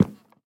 Minecraft Version Minecraft Version latest Latest Release | Latest Snapshot latest / assets / minecraft / sounds / block / cherry_wood / step1.ogg Compare With Compare With Latest Release | Latest Snapshot
step1.ogg